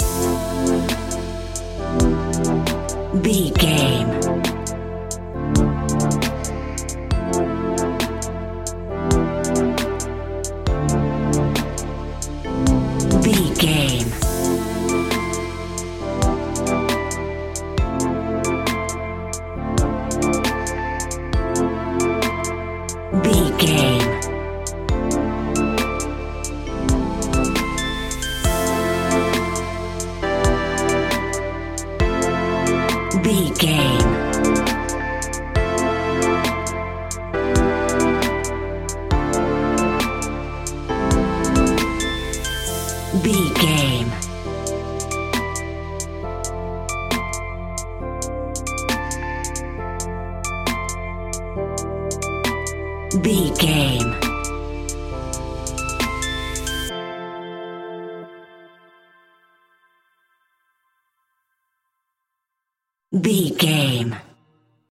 Chilled Hip Hop Ballad Music 60 Sec.
Aeolian/Minor
hip hop
laid back
hip hop drums
hip hop synths
piano
hip hop pads